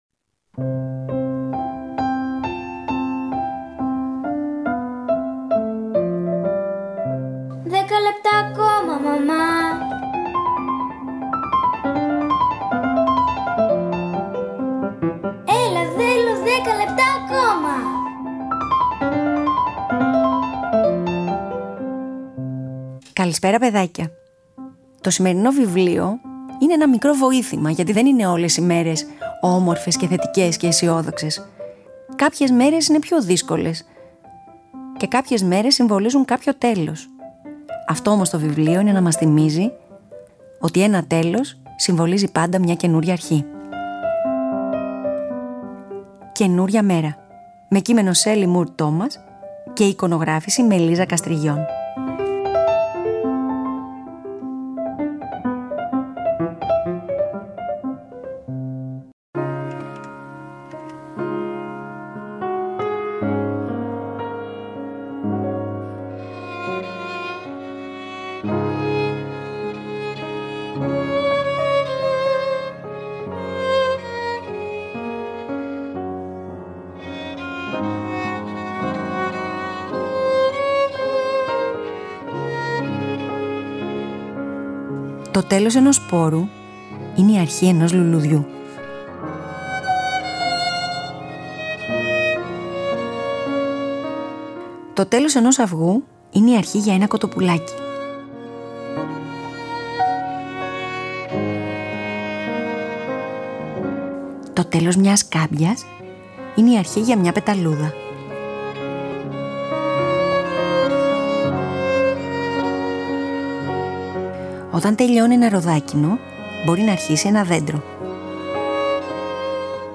Αφήγηση-Μουσικές επιλογές: